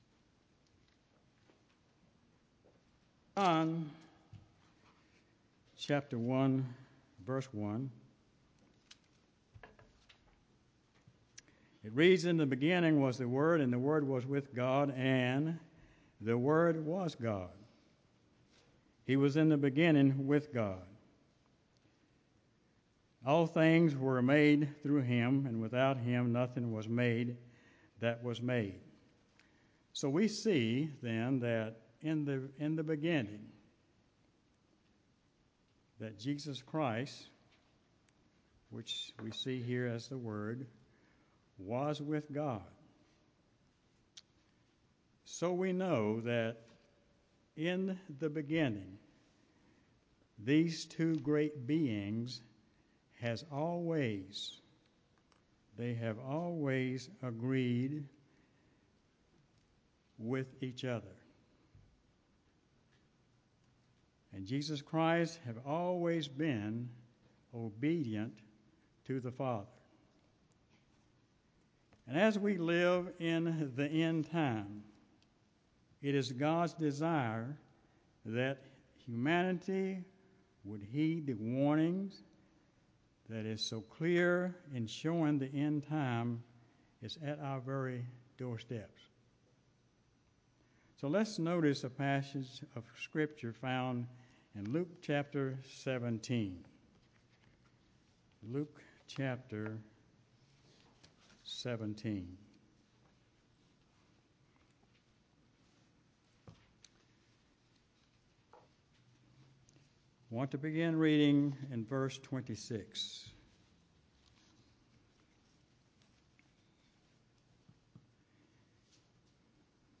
Given in Greensboro, NC
UCG Sermon Studying the bible?